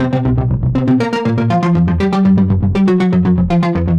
Index of /musicradar/french-house-chillout-samples/120bpm/Instruments
FHC_Arp A_120-C.wav